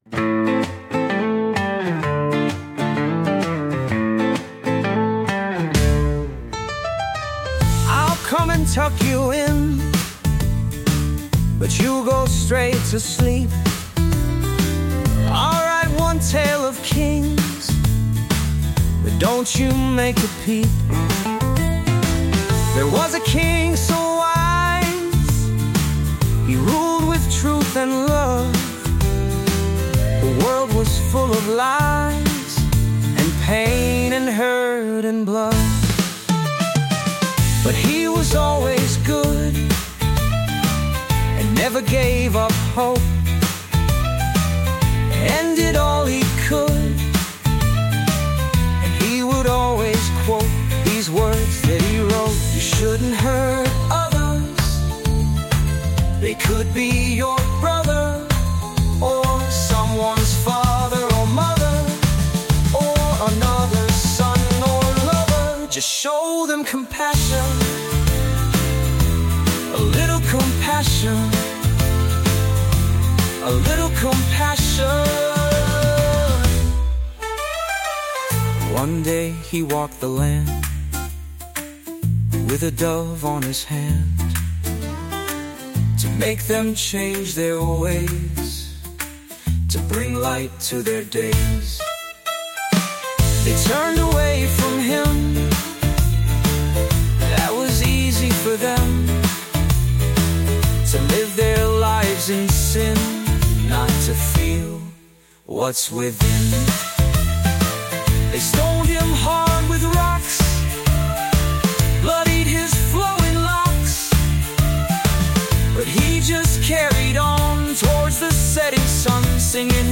heartfelt and powerful children’s song